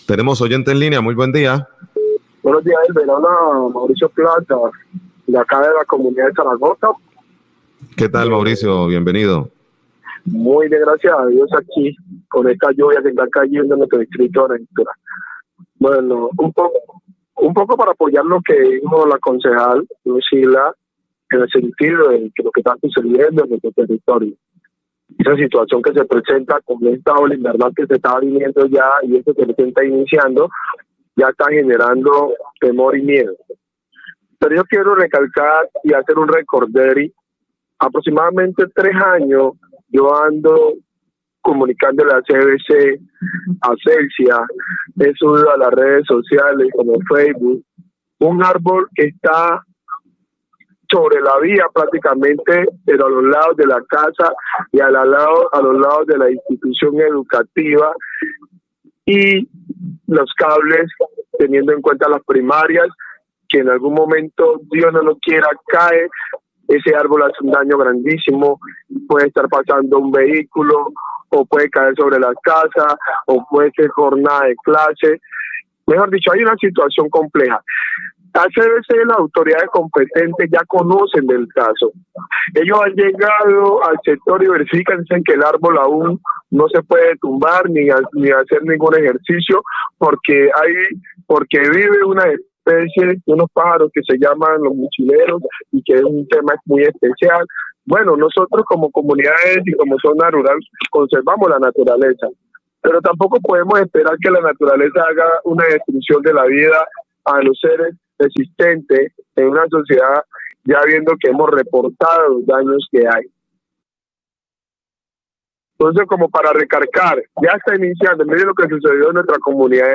Oyente hace llamado a Celsia y CVC para podar arbol aledaño a colegio y redes de energía
Radio